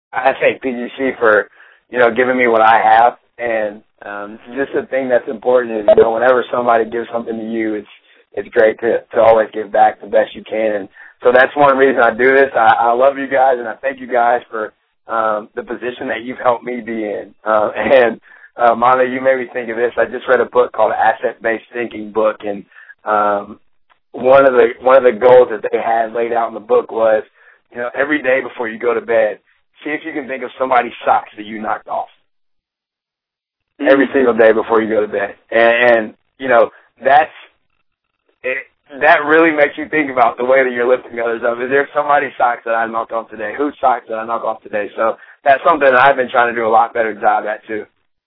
My hope is that you all found and will find value in the PGC Online Training quarterly interviews with college athletes and coaches while you are in the midst of training in order to reach your basketball aspirations.